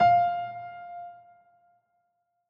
files AfterStory/Doki Doki Literature Club/game/mod_assets/sounds/piano_keys
F5.ogg